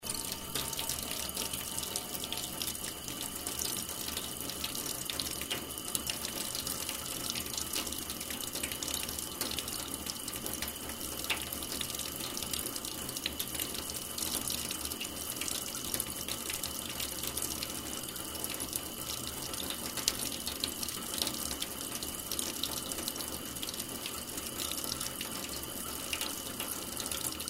Water Sound Effects
TapBasin_03.mp3